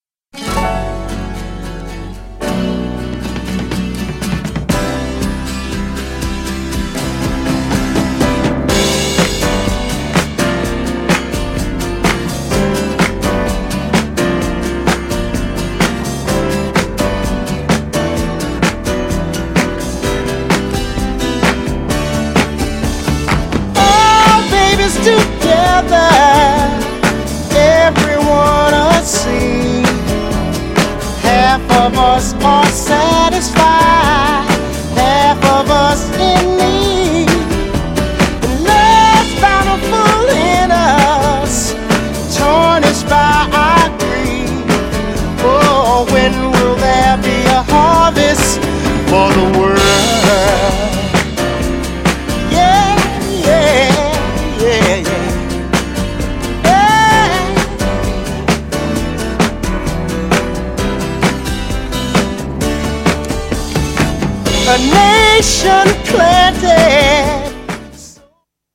GENRE Dance Classic
BPM 101〜105BPM